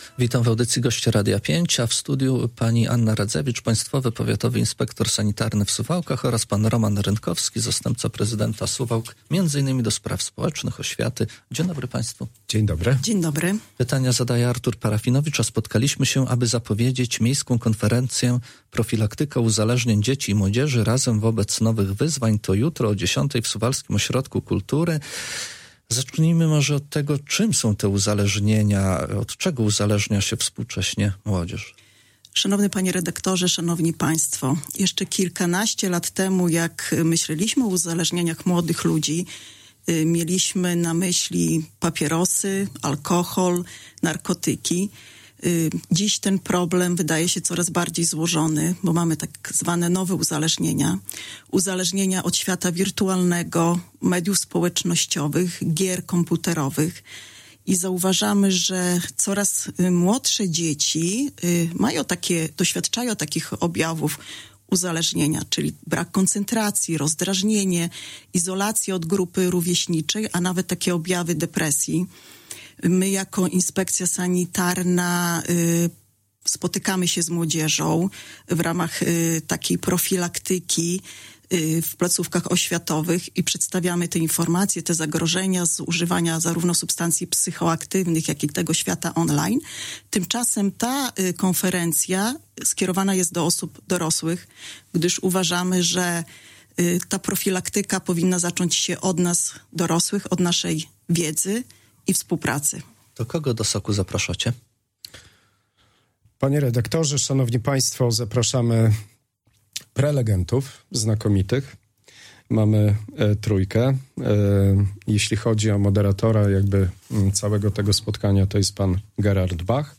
Na konferencję poświęconą uzależnieniom młodzieży zapraszali w poniedziałek (27.10) w Radiu 5 Anna Radzewicz, Państwowy Powiatowy Inspektor Sanitarny w Suwałkach i Roman Rynkowski, zastępca prezydenta Suwałk do spraw społecznych.